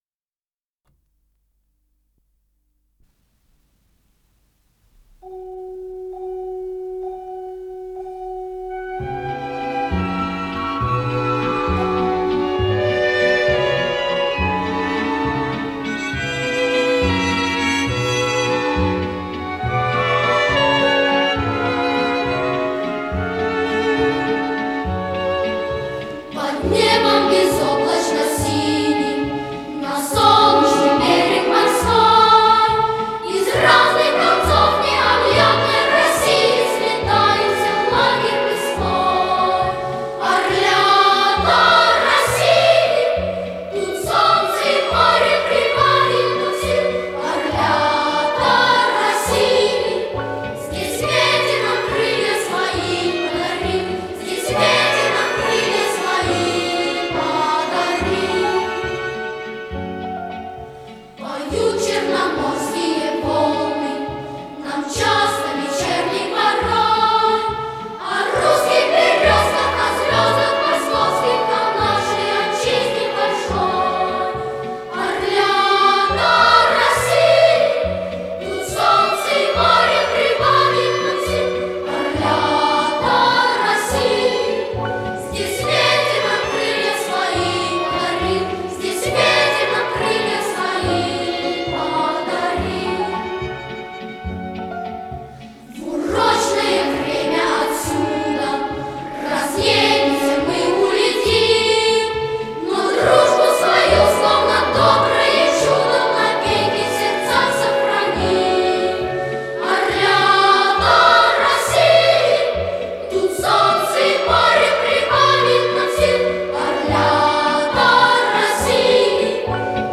РедакцияМузыкальная детская
АккомпаниментИнструментальный ансамбль
ВариантДубль моно